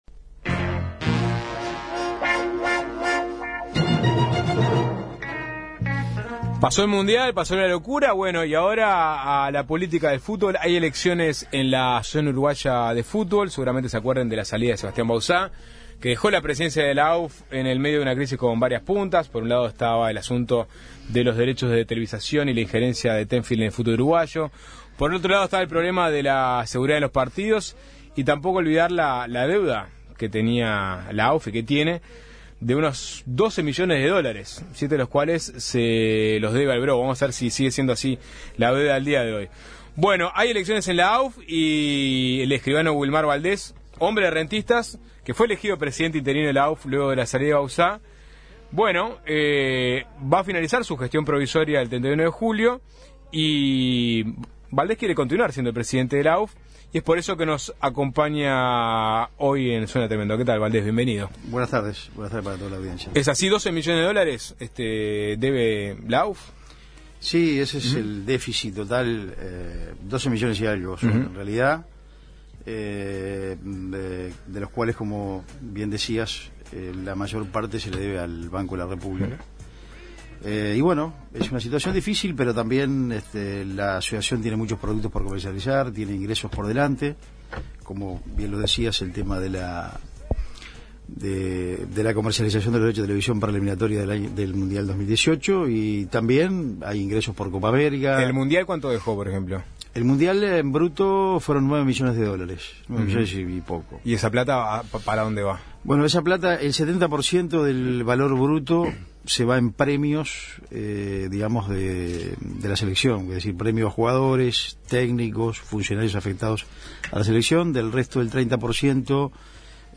El actual presidente interino y candidato a la presidencia efectiva de la Asociación Uruguaya de Fútbol (AUF), Wilmar Valdez, pasó por Suena Tremendo. Conversamos de los derechos de televisación del fútbol uruguayo y la relación con la empresa Tenfield, entre otras cosas.